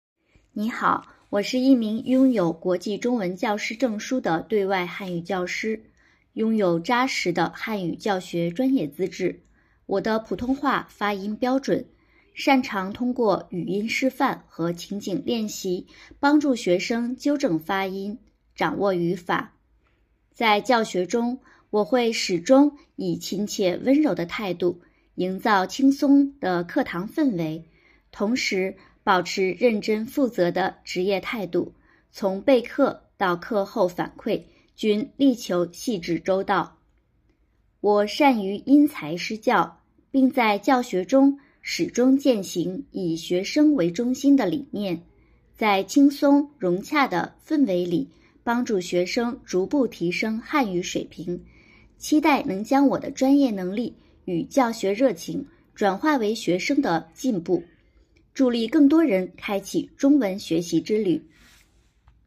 音声の自己紹介